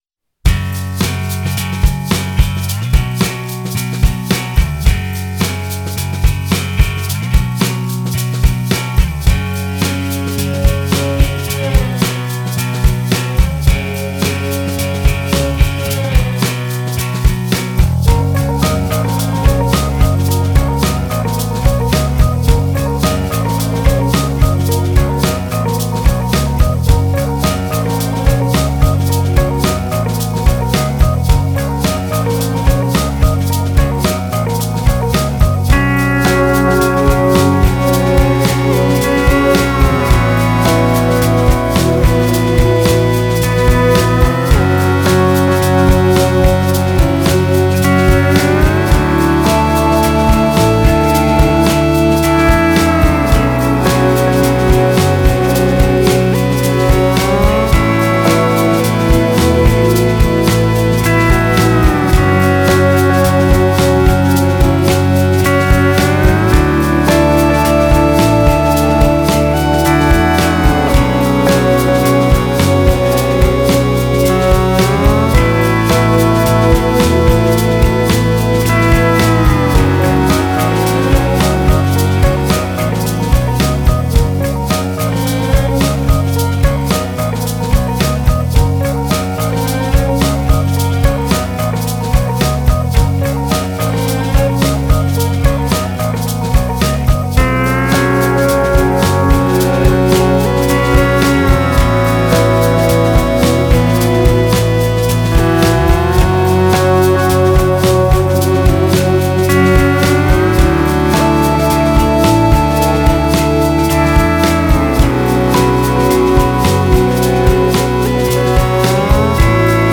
Multi-member instrumentalist group